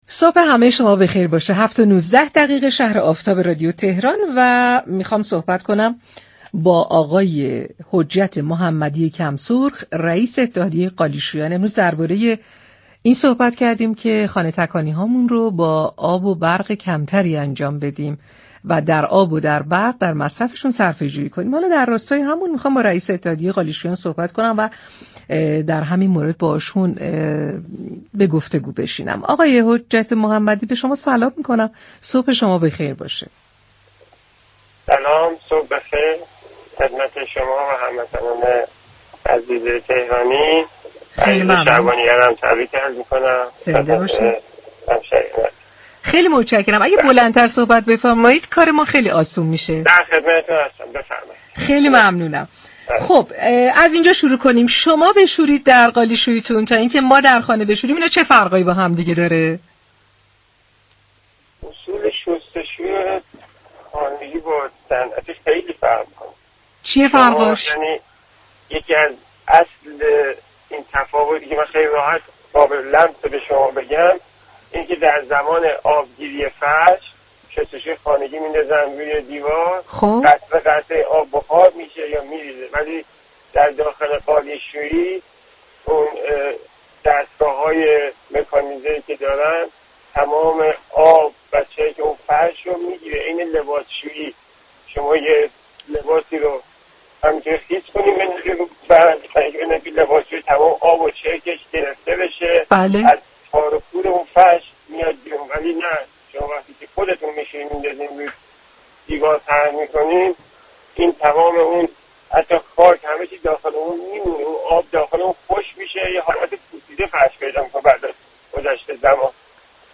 در گفت و گو با «شهر آفتاب» رادیو تهران